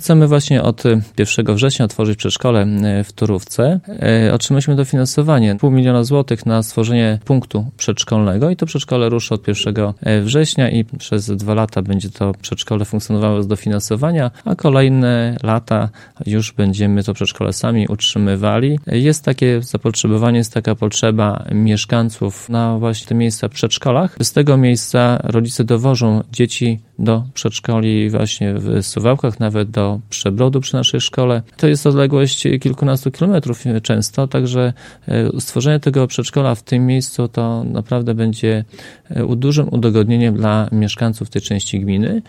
O szczegółach mówił w czwartek (30.05) w Radiu 5 Zbigniew Mackiewicz, wójt gminy Suwałki.